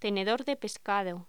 Locución: Tenedor de pescado